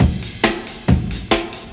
• 139 Bpm Breakbeat D# Key.wav
Free drum loop - kick tuned to the D# note. Loudest frequency: 1171Hz
139-bpm-breakbeat-d-sharp-key-Ukh.wav